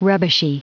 Prononciation du mot rubbishy en anglais (fichier audio)
Prononciation du mot : rubbishy